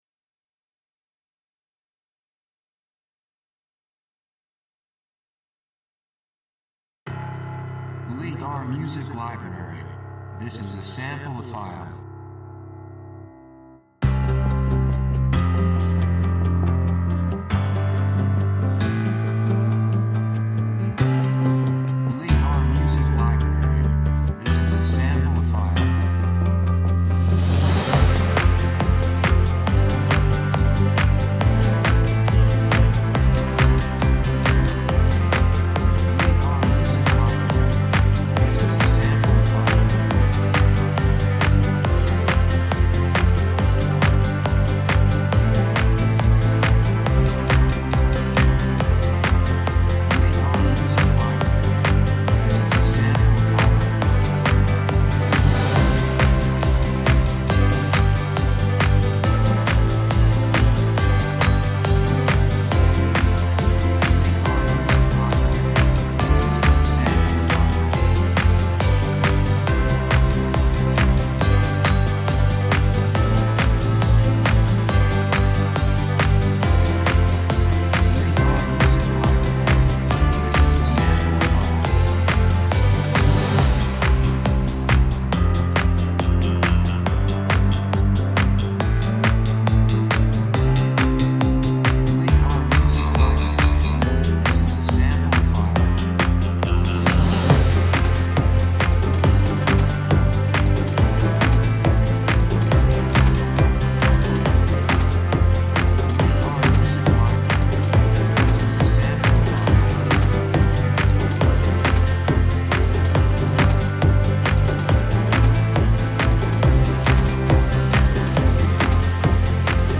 雰囲気幸せ, 高揚感, 決意, 喜び
曲調ポジティブ
楽器ピアノ, ストリングス, ボーカル, 手拍子
テンポ速い